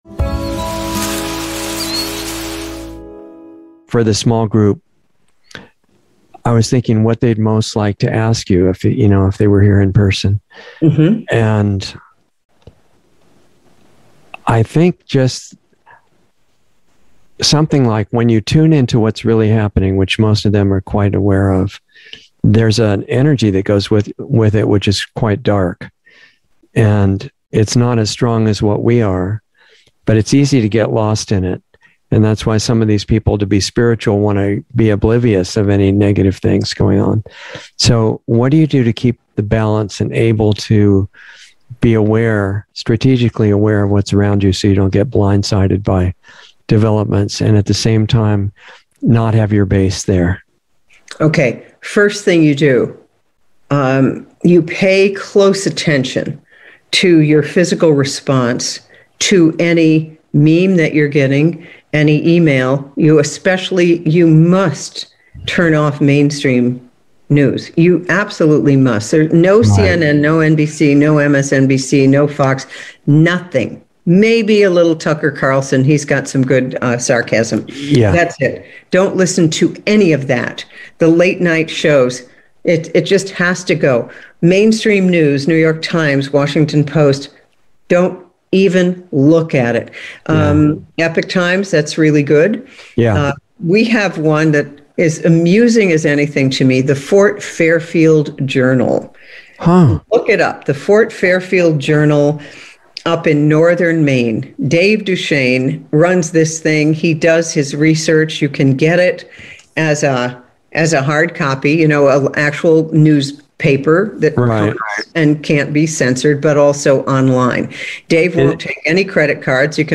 Planetary Healing Club - Dr. Christiane Northrup - Insider Interview 10/21/21